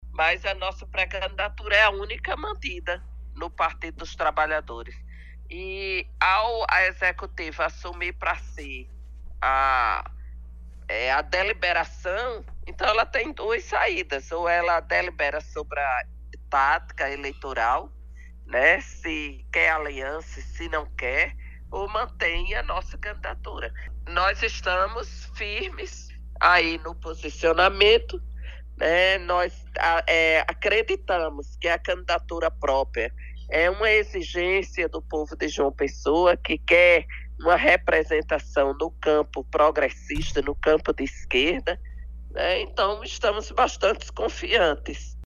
Os comentários da parlamentar foram registrados pelo programa Correio Debate, da 98 FM, de João Pessoa, nesta sexta-feira (05/04).